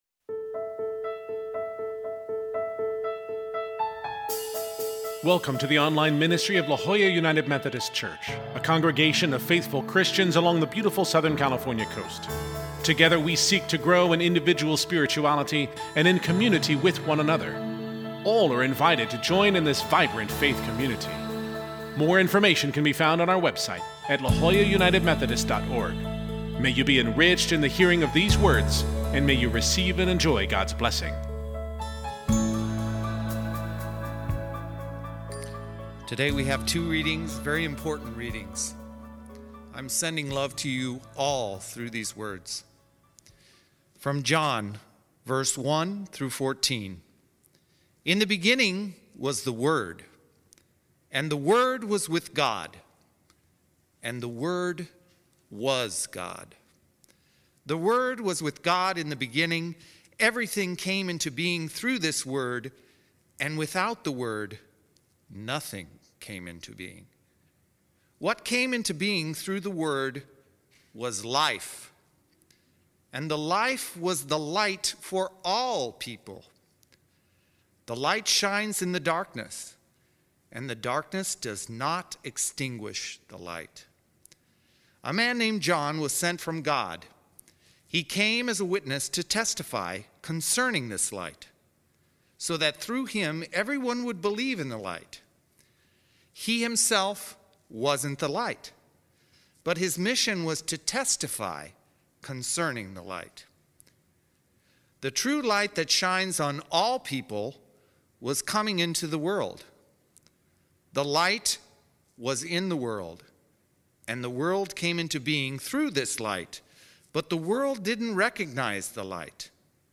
As we worship online, let us continue to pray for our community as we respond to the COVID-19 pandemic. This week combines the opening of the Gospel of John with the opening of the book of Genesis, both creation stories.